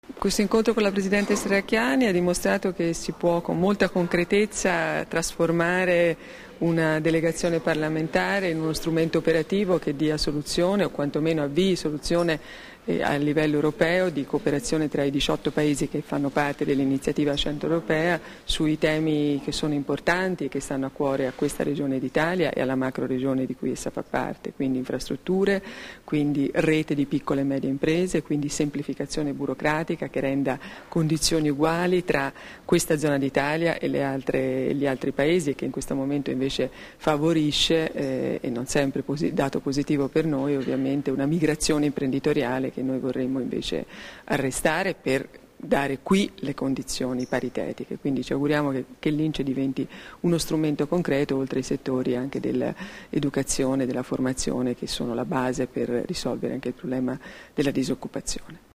Dichiarazioni di Stefania Giannini, neoeletta presidente della Delegazione parlamentare italiana presso l'Assemblea parlamentare dell'InCE-Iniziativa Centro Europea (Formato MP3) [945KB]
rilasciate a margine dell'incontro con Debora Serracchiani, a Trieste il 10 gennaio 2014